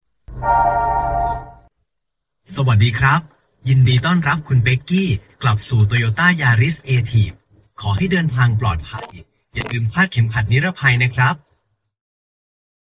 เสียงต้อนรับสำหรับ CarPlay (เสียงผู้ชาย)
หมวดหมู่: เสียงเรียกเข้า
welcome-voice-for-carplay-male-voice-th-www_tiengdong_com.mp3